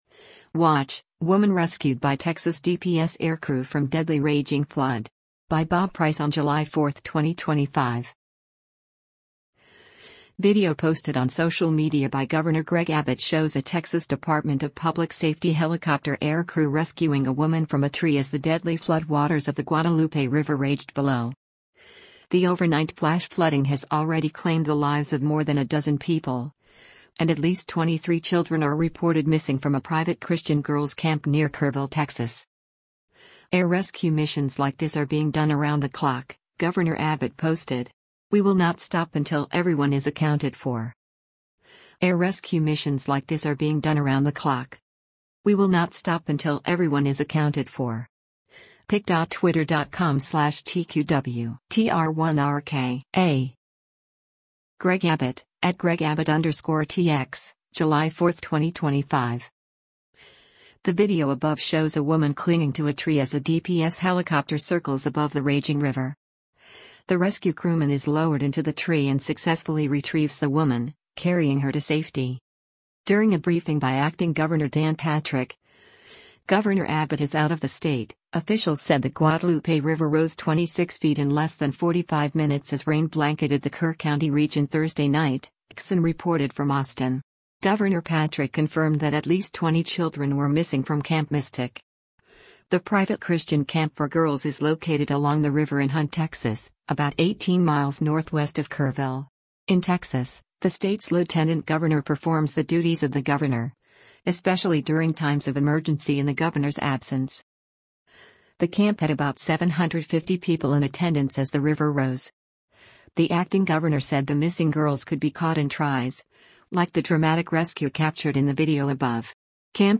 The video above shows a woman clinging to a tree as a DPS helicopter circles above the raging river. The rescue crewman is lowered into the tree and successfully retrieves the woman, carrying her to safety.